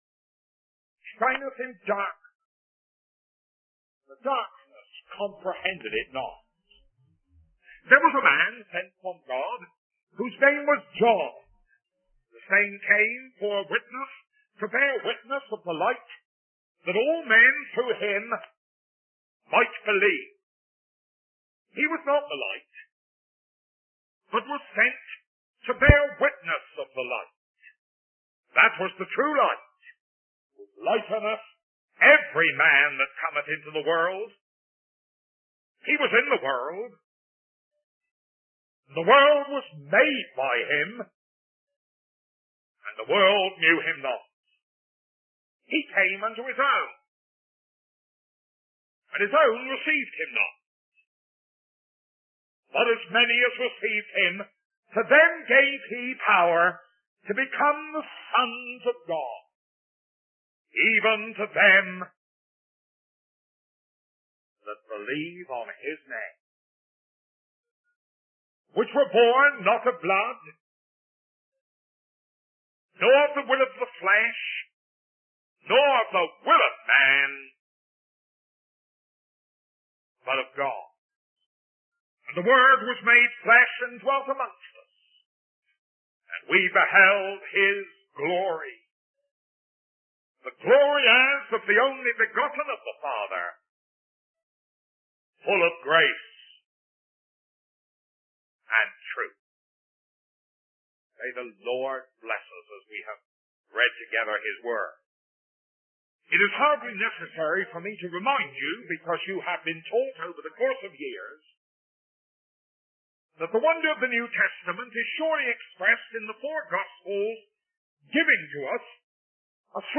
In this sermon, the preacher begins by emphasizing that he is not here to talk about a mere man, but about a great God. He then focuses on the first chapter of the Gospel of John and highlights four important aspects. Firstly, Jesus Christ is introduced as a searcher, someone who seeks out and cares for the lost souls.